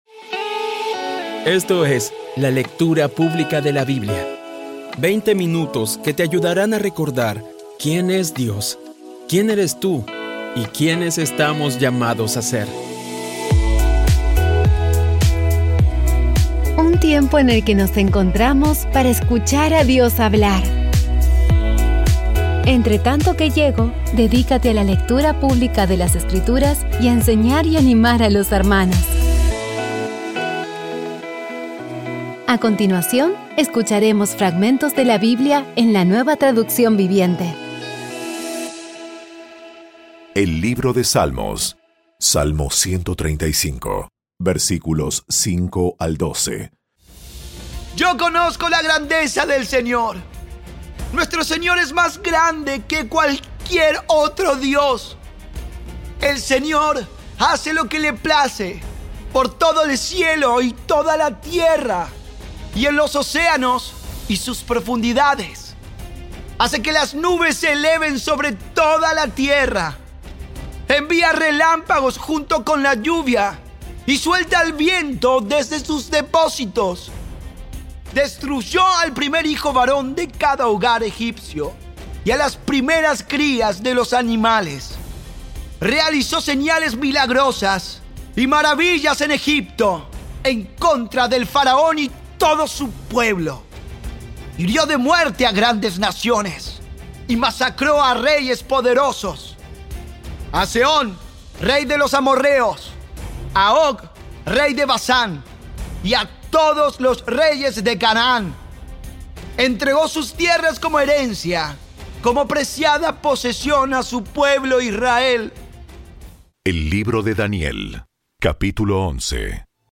Audio Biblia Dramatizada Episodio 335
Poco a poco y con las maravillosas voces actuadas de los protagonistas vas degustando las palabras de esa guía que Dios nos dio.